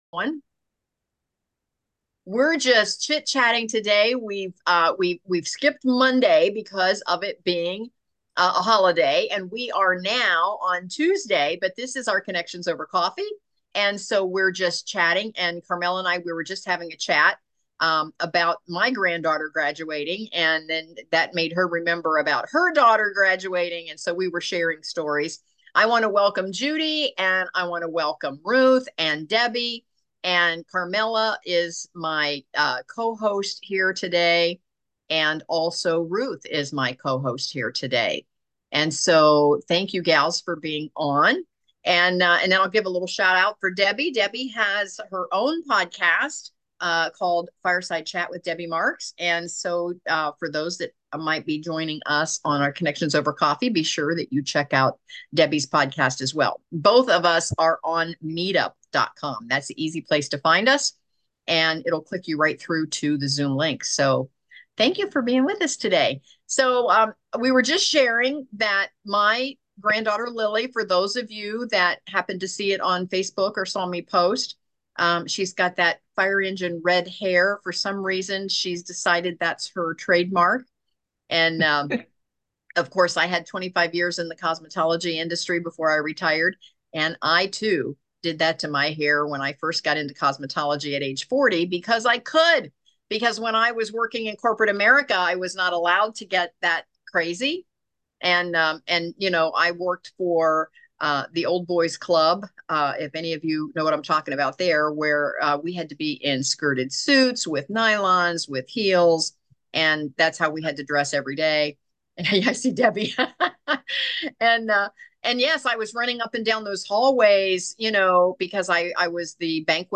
The video replay of Connections Over Coffee's virtual party on Zoom is a vibrant journey through the four steps of RISE: RECONNECT, IMPACT, STORIFY, and ELEVATE.